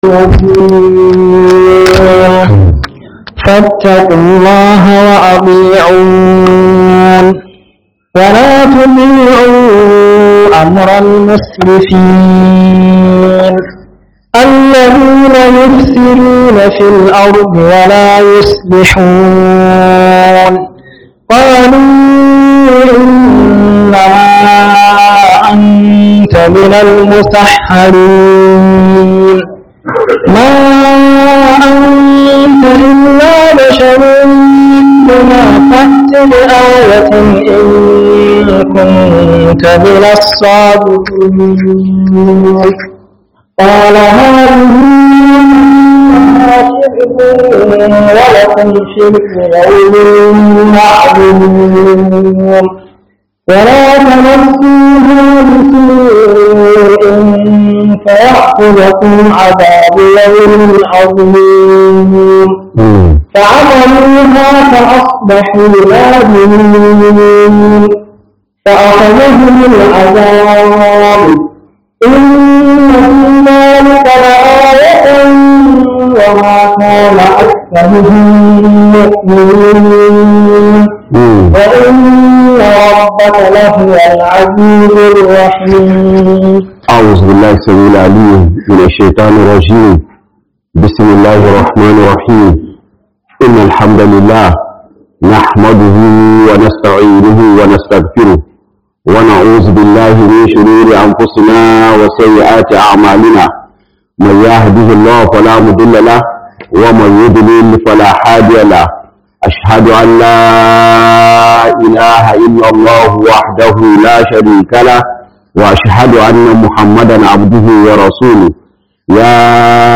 08 Ramadan Tafsir 1447H